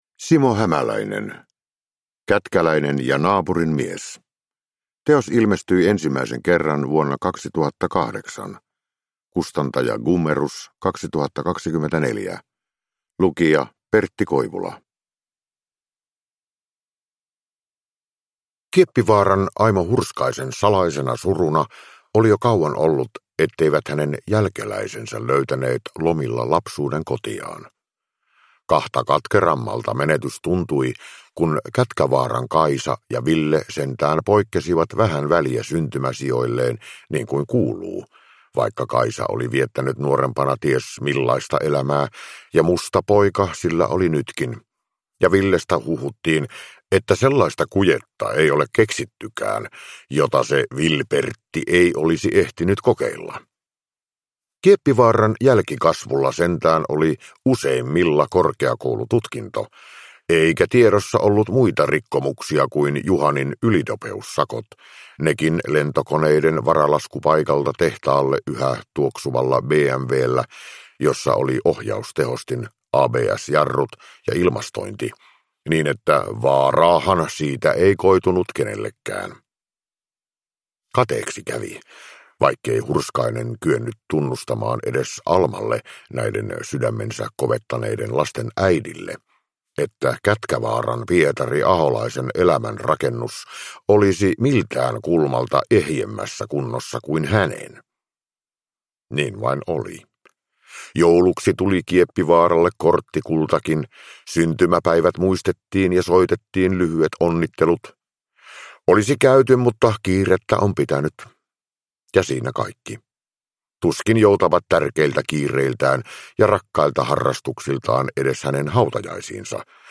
Kätkäläinen ja naapurin mies – Ljudbok